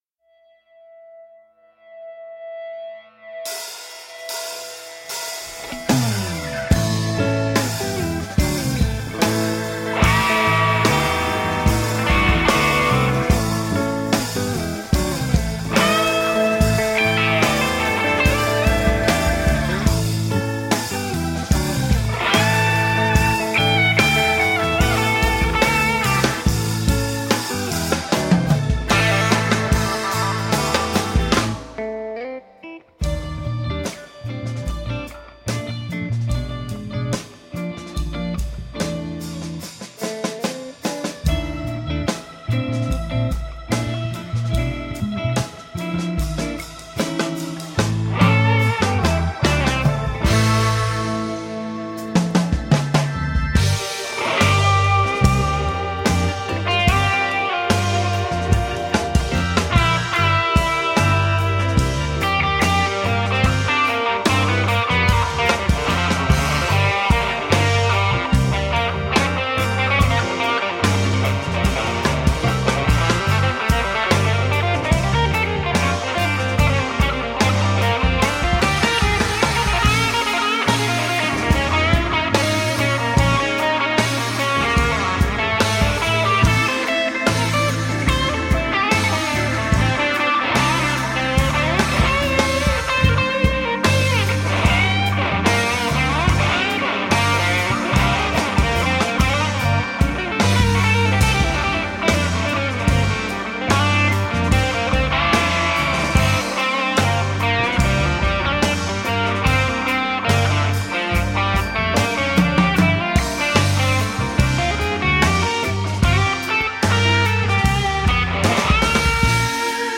Tagged as: Jazz, Blues, Instrumental Jazz, World Influenced